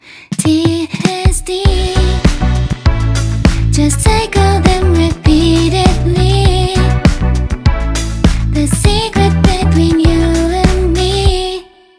さてそれでは、ここから怒涛の視聴タイムです!! 4コードのループで、TDSの違いを感じてみましょう。今回は単一のメロディを使い回して、さまざまなコード進行をあてていくことにします。
始まりのTからいきなりピークであるDに達し、そこからゆっくりと緊張を緩めていくという流れになります。